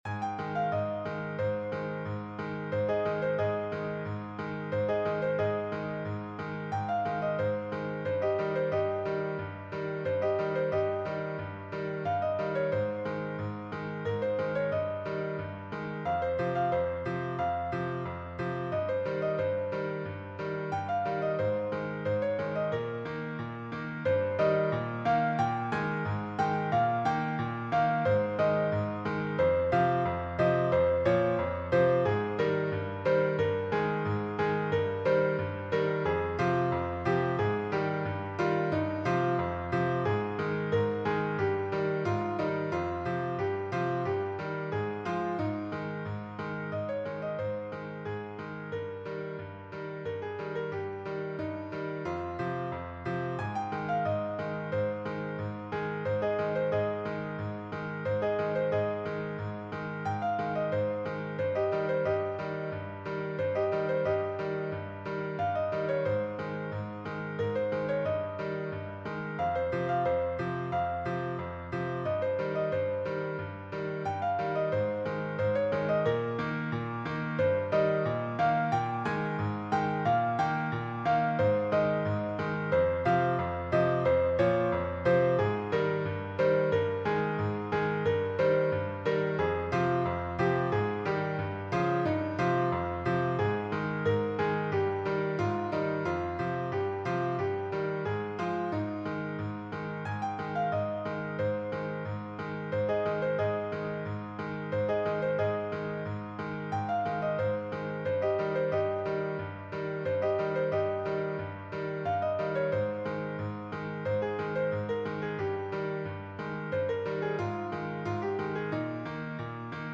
Melancholic
Modern ragtime
Piano and voice